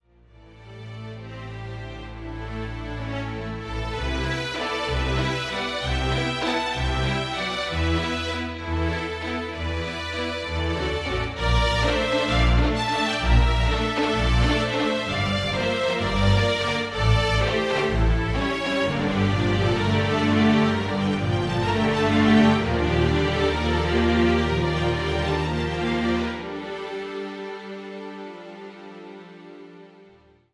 多種多様ながらも個性的な音楽が盛り沢山！！